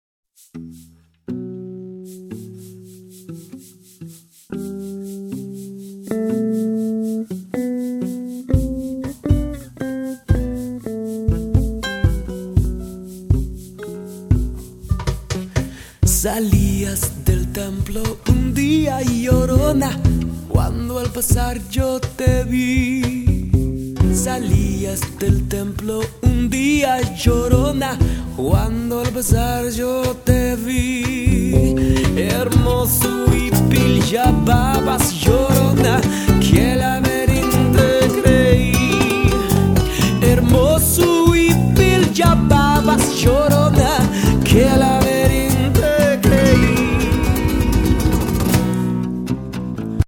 This CD takes his Latin Soul & Groove sound to new heights!